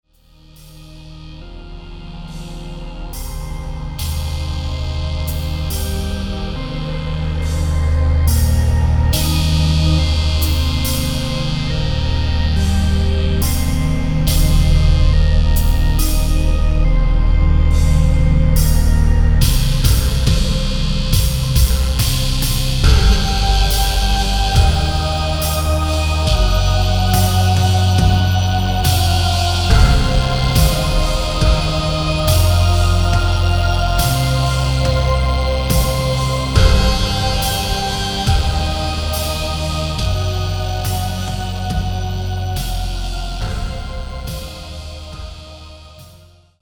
(atmospheric funerary doom)